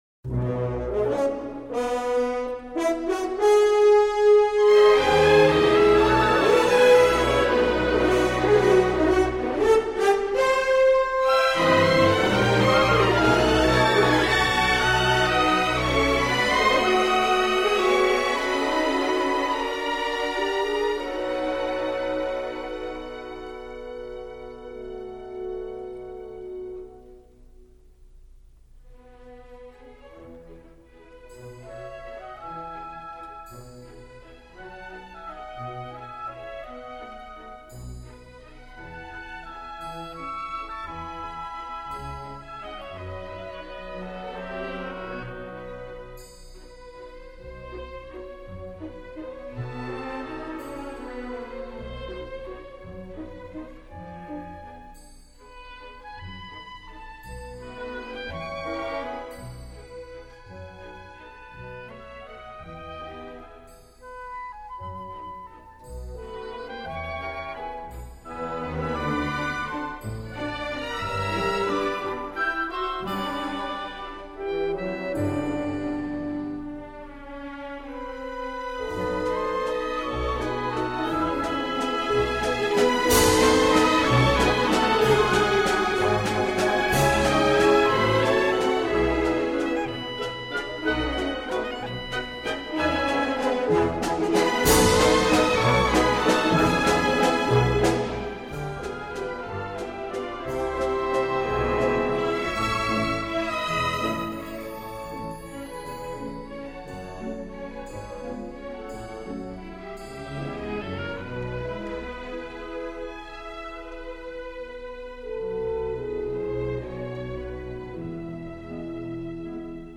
Sono anche venuto a sapere che Stanley Kubrick non aveva alcuna preferenza musicale per questo film, ma che voleva sentire il valzer di Strauss Der Rosenkavalier [